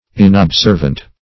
Inobservant \In`ob*serv"ant\, a. [L. inobservans.